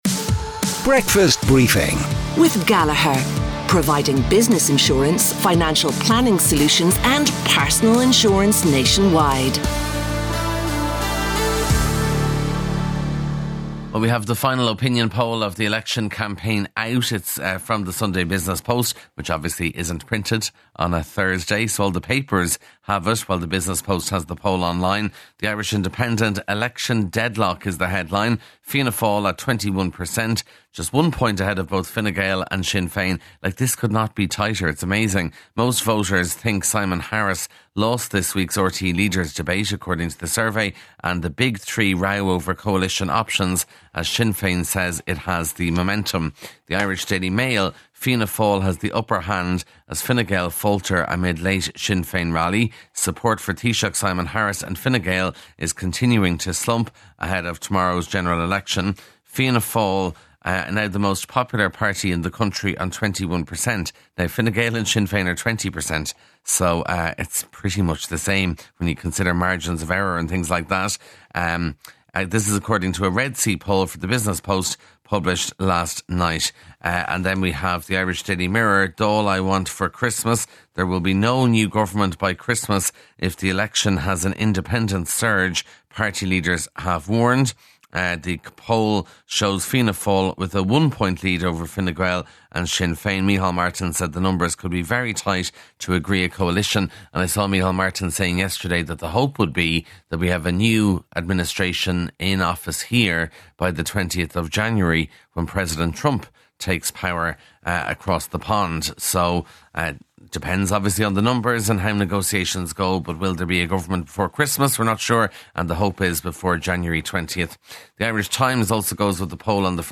LIVE from 6am each weekday morning
breakfast_briefing_newspaper_rev_62deb996_normal.mp3